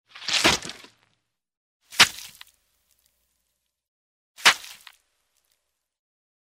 Звуки ужаса
На этой странице собраны самые жуткие звуки ужаса: скрипы, стоны, шаги в темноте, леденящие душу крики и другие пугающие эффекты.
Разделывают тело